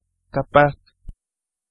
Ääntäminen
IPA: /ɛk.spe.ʁi.mɑ̃.te/